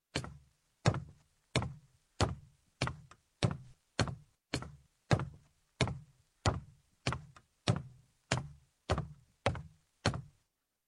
walk up stairs